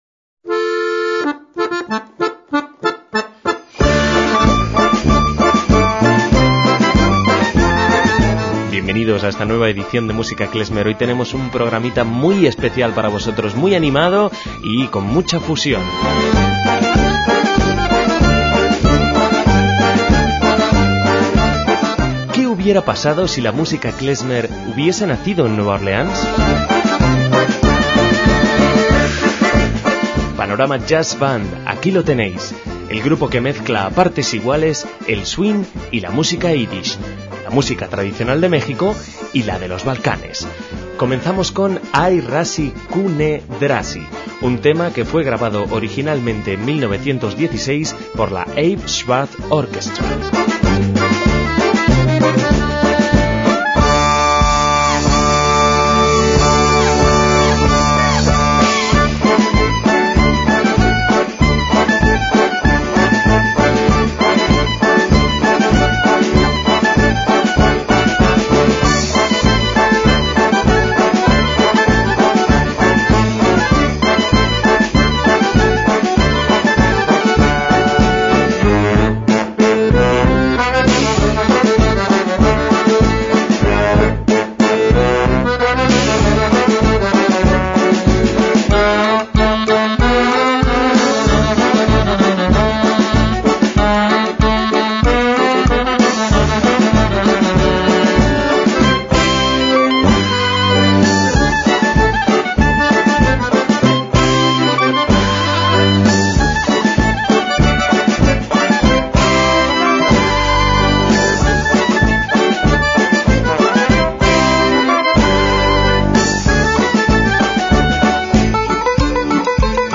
MÚSICA KLEZMER
saxo alto
trombón
acordeón
banjo tenor
sousaphone, una especie de tuba de desfile